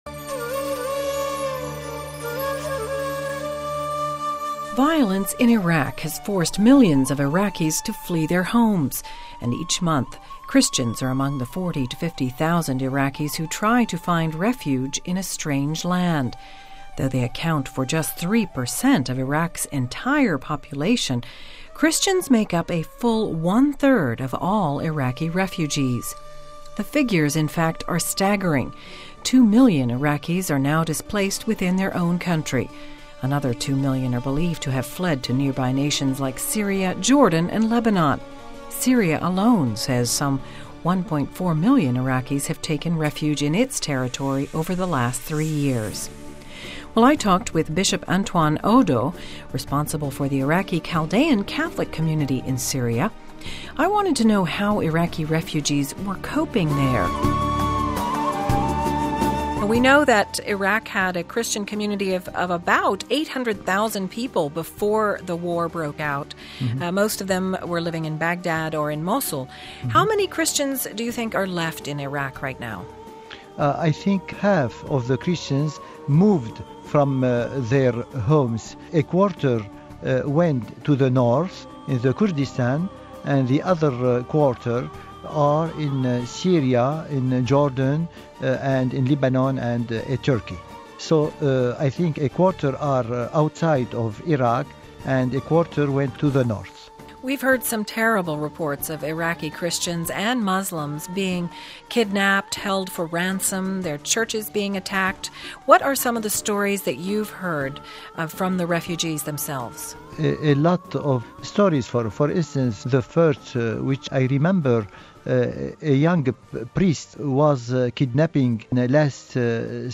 Iraqi Christians are among the more than 1.3 million refugees who've fled the violence in their homeland for safety in nearby Syria. We talk to Bishop Antoine Audo sj., responsible for the Chaldean Catholic Community there...